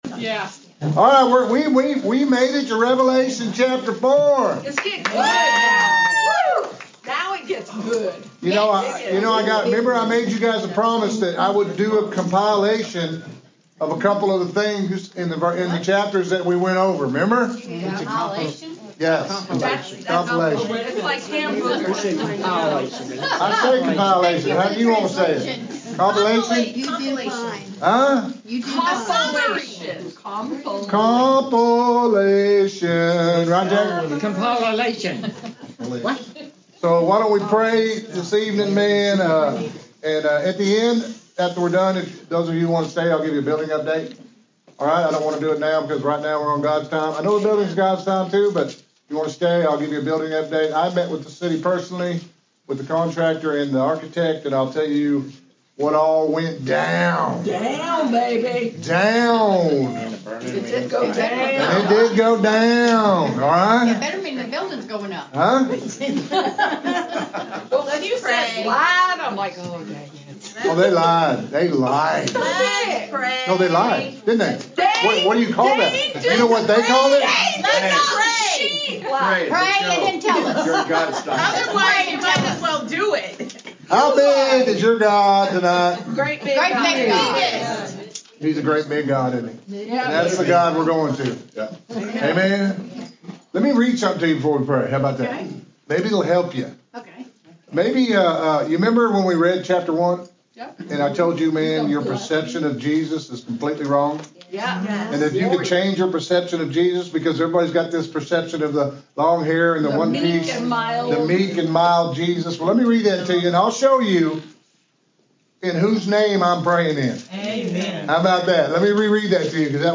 Interactive Bible Study
Sermon Audio